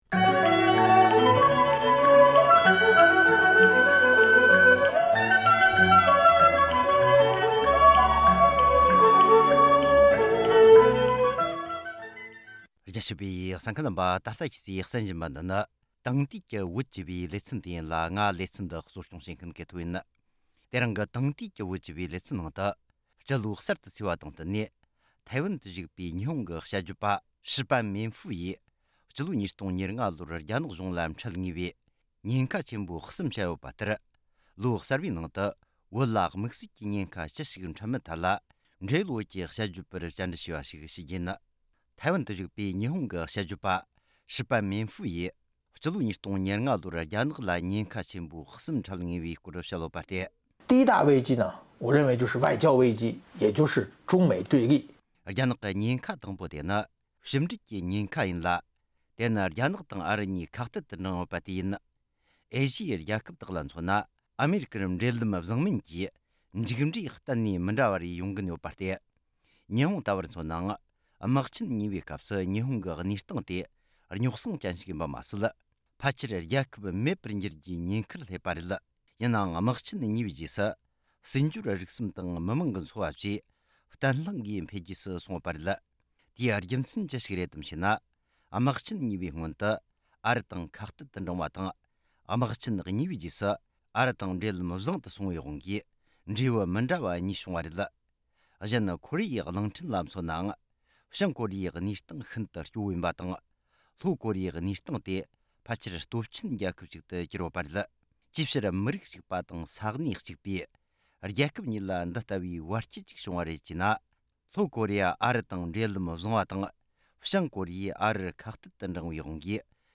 བཅར་འདྲི་དང་གནས་ཚུལ་ཕྱོགས་བསྡུས་བྱས་པ་ཞིག་ལ་གསན་རོགས་གནོངས།